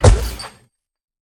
Minecraft Version Minecraft Version snapshot Latest Release | Latest Snapshot snapshot / assets / minecraft / sounds / mob / ravager / step3.ogg Compare With Compare With Latest Release | Latest Snapshot
step3.ogg